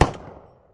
m16_shot.wav